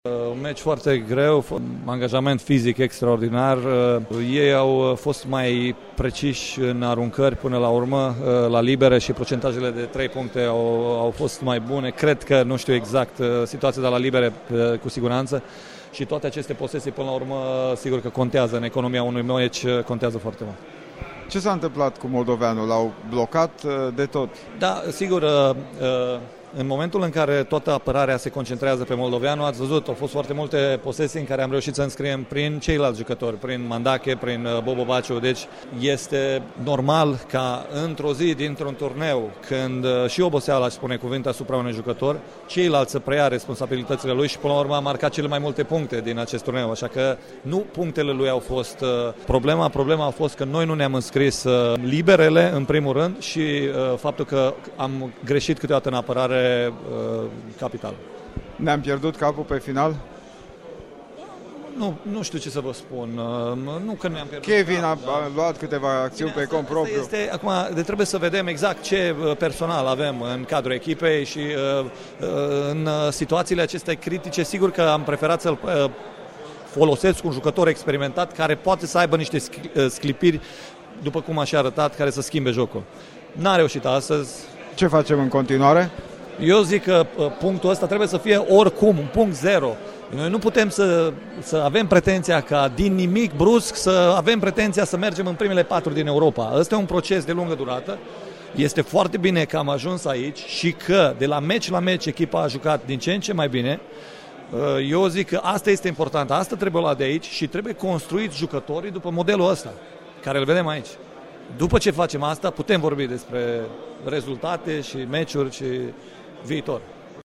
într-un interviu